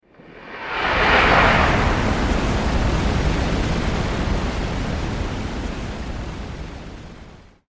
Rocket Engine Sound Effect
Designed sound effect of a large rocket engine blast. Powerful, intense, and cinematic.
Rocket-engine-sound-effect.mp3